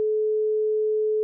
A 430Hz tone
430Hz.wav